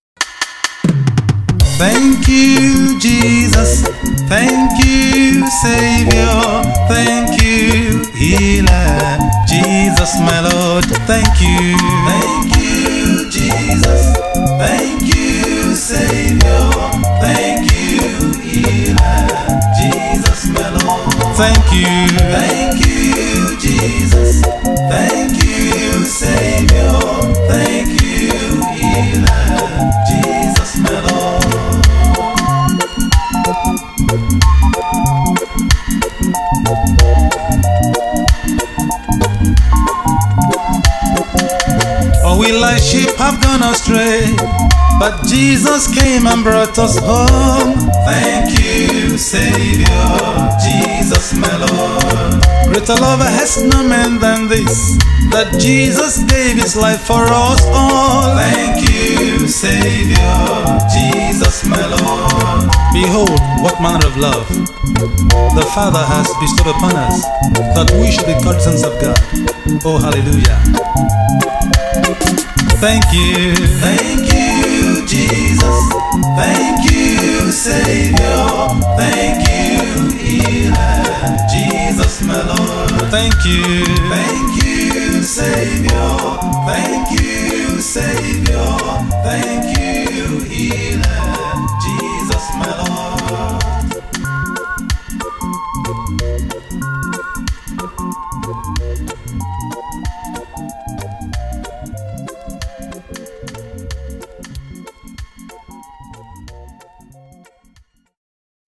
January 20, 2025 Publisher 01 Gospel 0